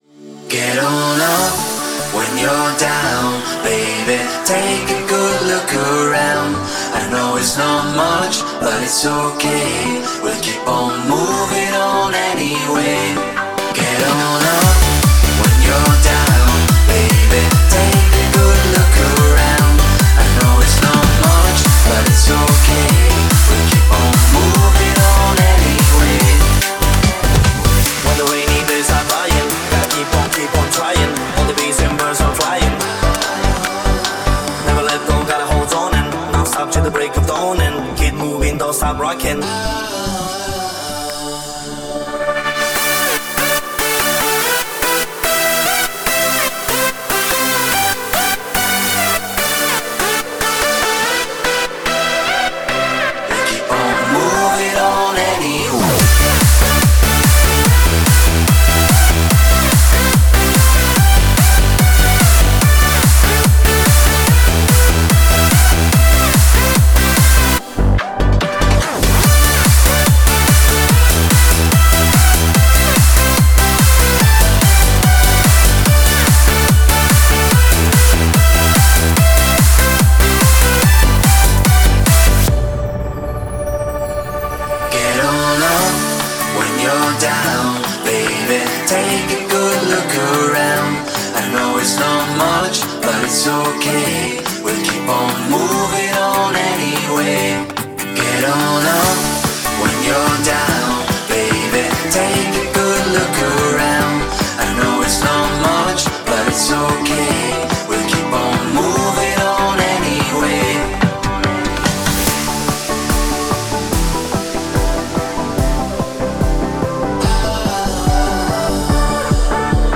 это энергичный трек в жанре евродэнс